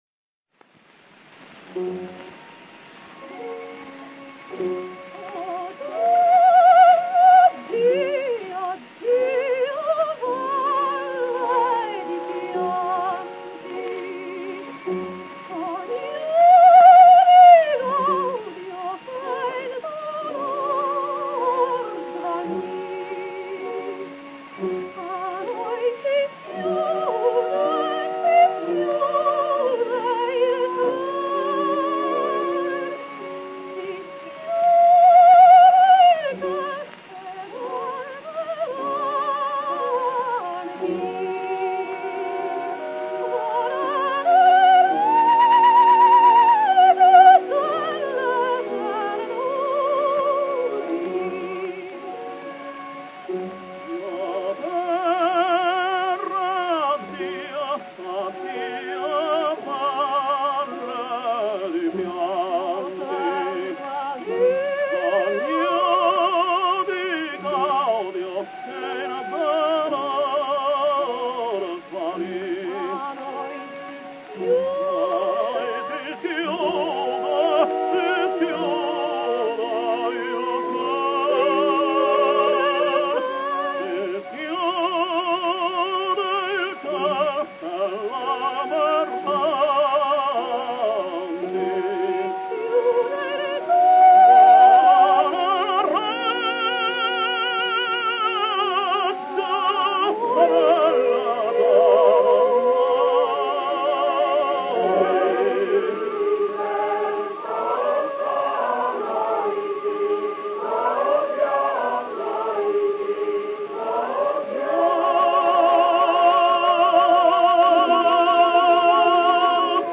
Gramophone, Milano, 27 April 1909